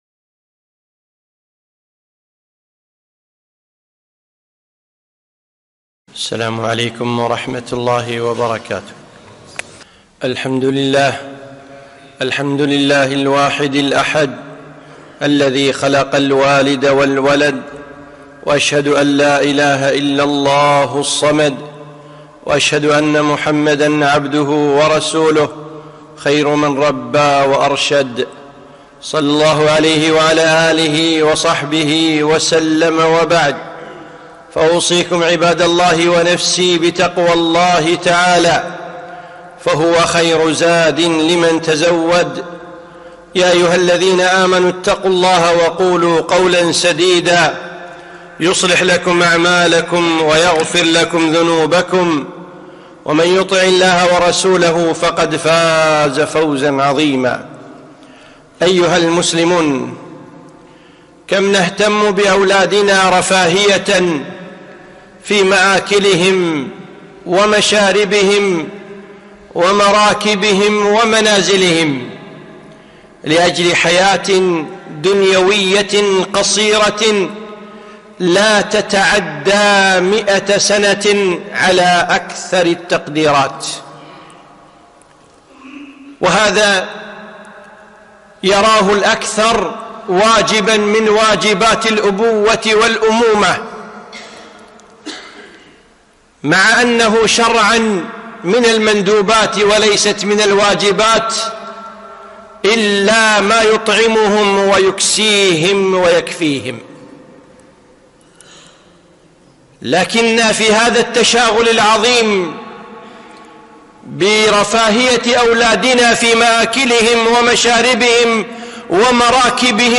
خطبة - دين أولادنا